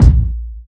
• Short Reverb Steel Kick Drum Sound A# Key 509.wav
Royality free bass drum one shot tuned to the A# note. Loudest frequency: 133Hz
short-reverb-steel-kick-drum-sound-a-sharp-key-509-p9W.wav